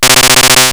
effect_thundershock2.wav